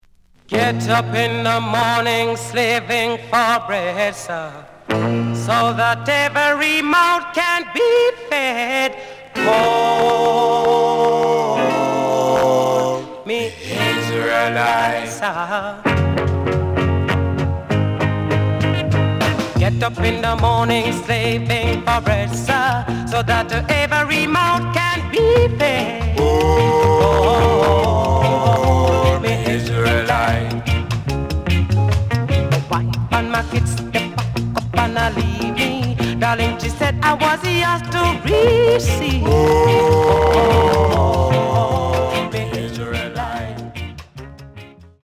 The audio sample is recorded from the actual item.
●Genre: Rock Steady
Slight sound craking on A side.